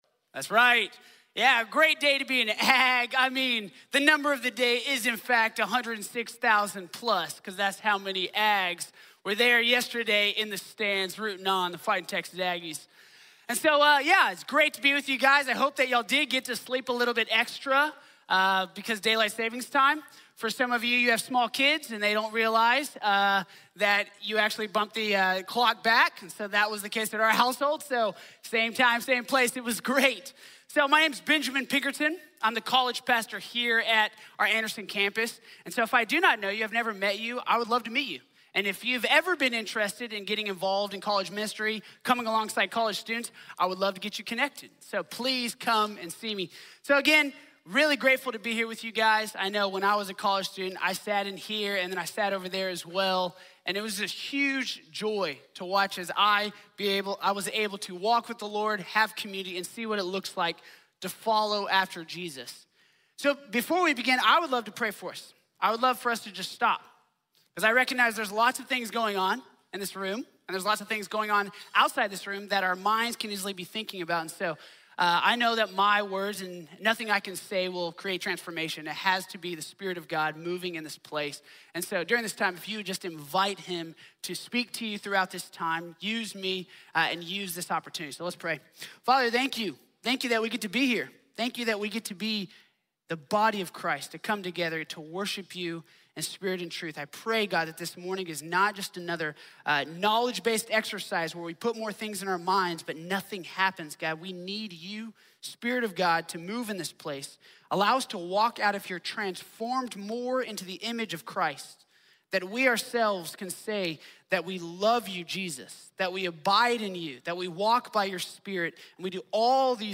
Making Mature Multipliers | Sermon | Grace Bible Church